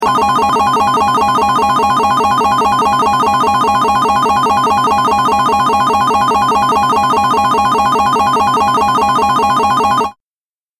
دانلود آهنگ هشدار 29 از افکت صوتی اشیاء
دانلود صدای هشدار 29 از ساعد نیوز با لینک مستقیم و کیفیت بالا
جلوه های صوتی